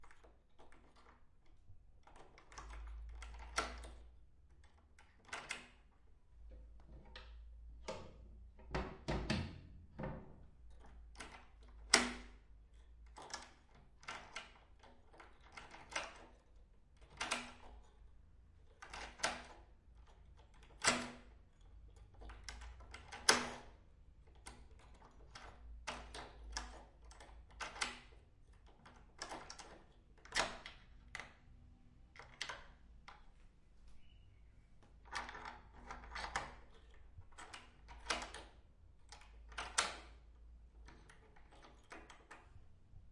乌干达 "木制门锁解锁死锁与旧钥匙开和关的问题
描述：门木int锁解锁deadbolt与旧钥匙和offmic.wav
Tag: 按键 木材 开锁 门栓 INT